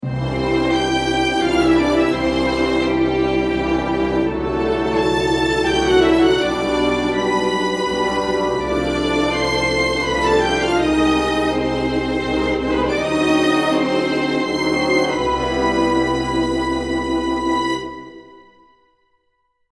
• Une doublure à l’unisson par une clarinette